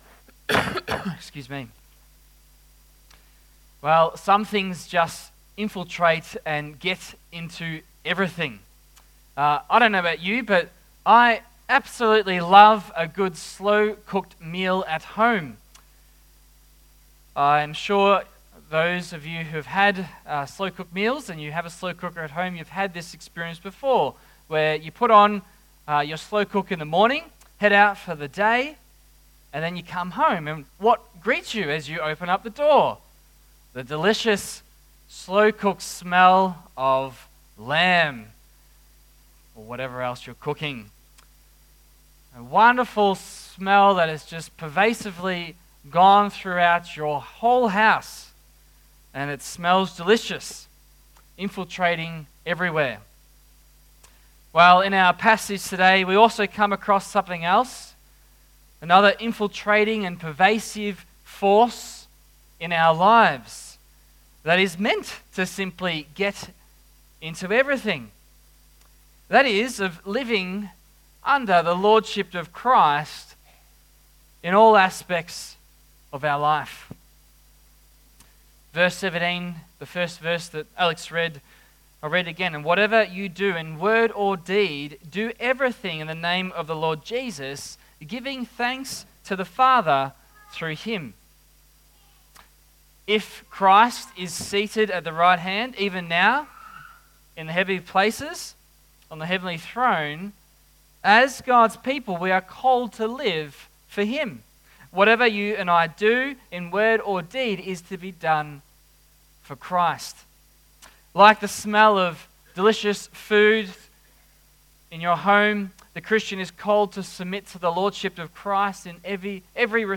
Christian Reformed Church of Geelong Sunday 20 th October 2024
Service Type: AM
Sermon